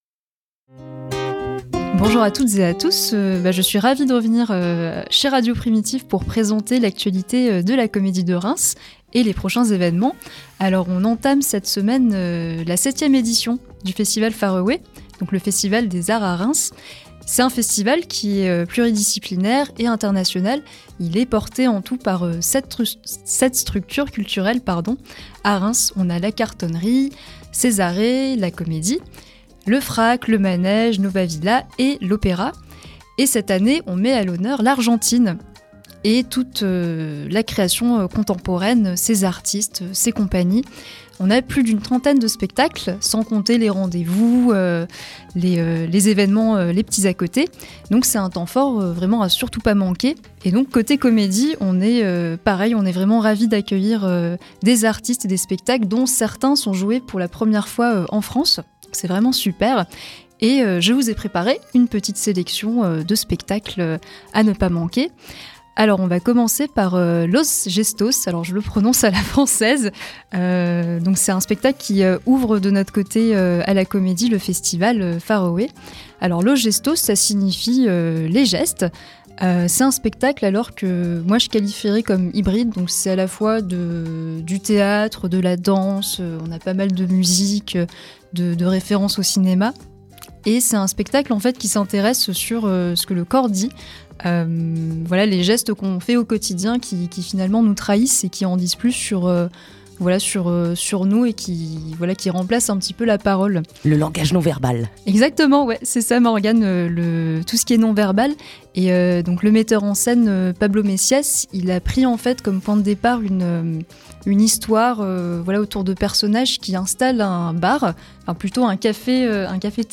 Chronique du 28 janvier (9:23)